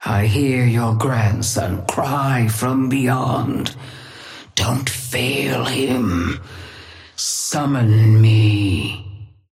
Sapphire Flame voice line - I hear your grandson cry from beyond. Don't fail him. Summon me.
Patron_female_ally_orion_start_08.mp3